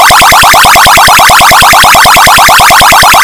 These sound effects are produced while the title screen is on display (see 24710).